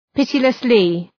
Προφορά
{‘pıtılıslı}